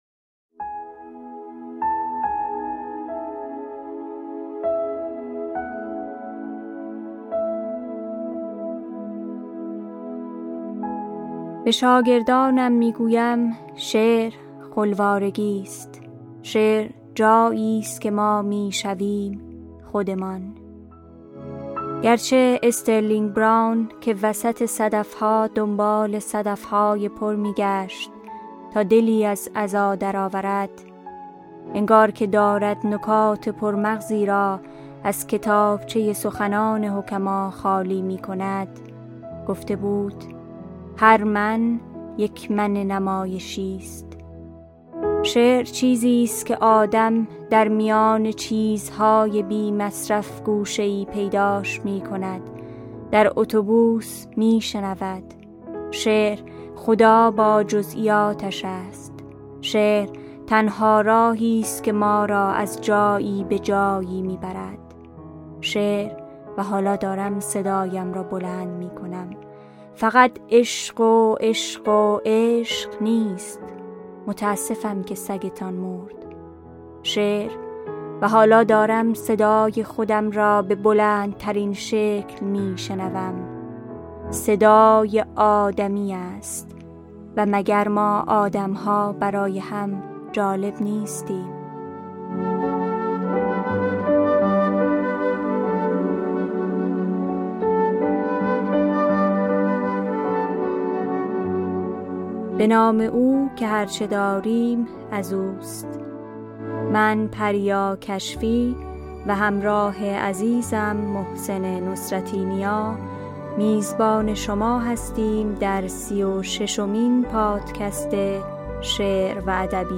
پادکست شعر سرایه ۳۶ – دکلمه شعرهایی از الیزابت الکساندر